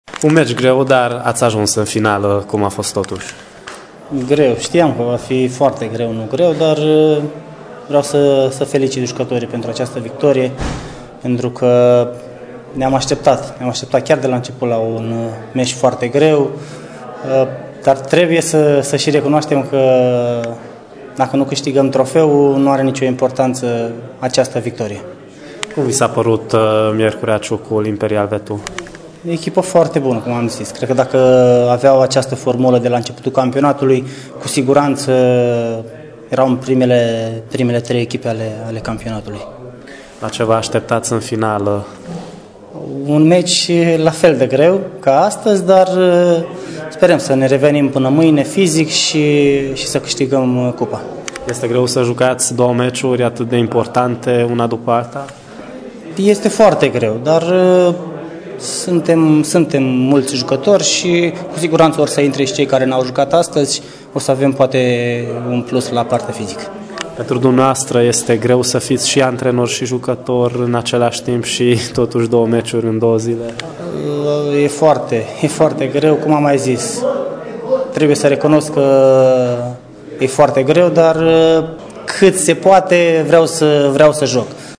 interviul